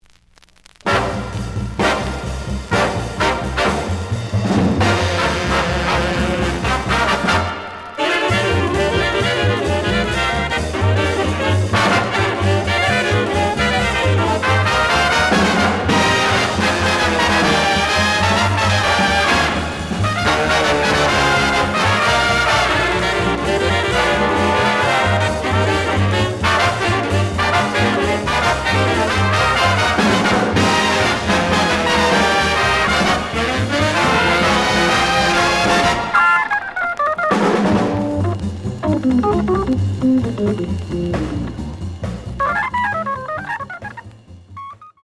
The audio sample is recorded from the actual item.
●Genre: Big Band